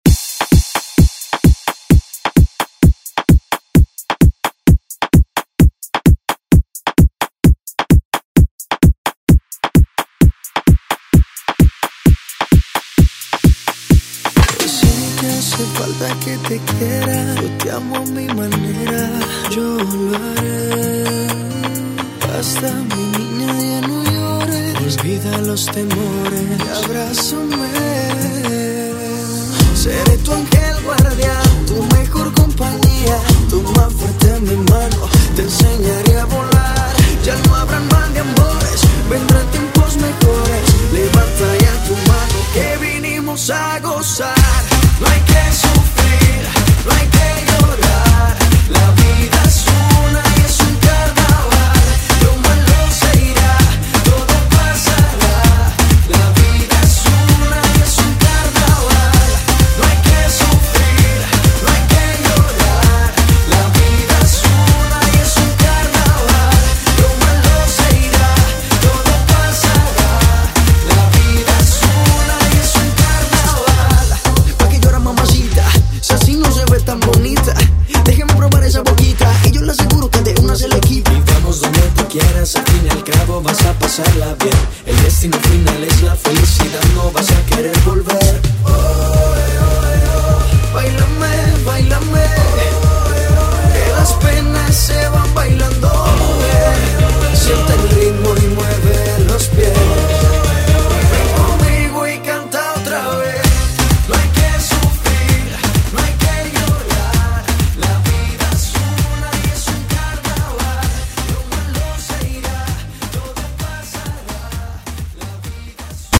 Genres: 90's , EDM , ROCK
Clean BPM: 125 Time